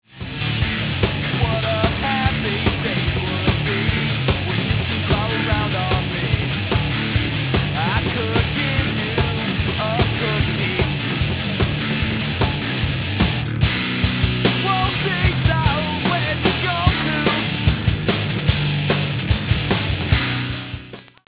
Heavy Metal, Hard Rock, College/Indie/Lo-Fi